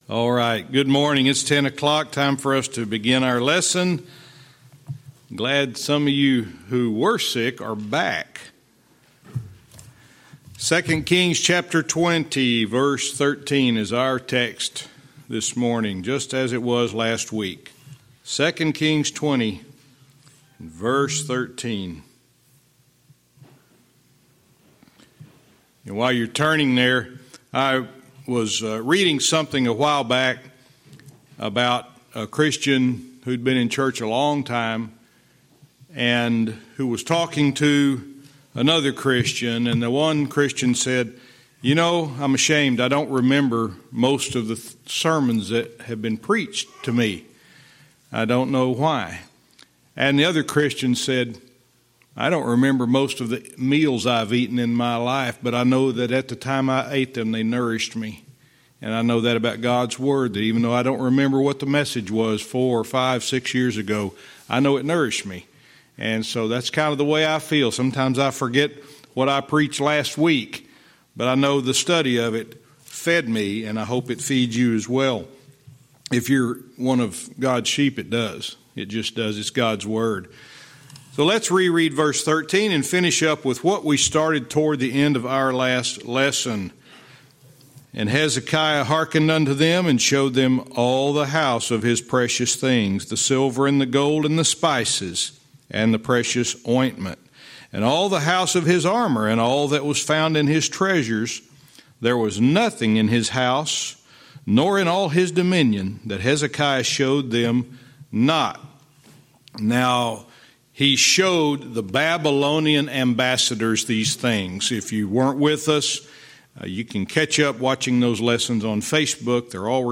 Verse by verse teaching - 2 Kings 20:13(cont)-18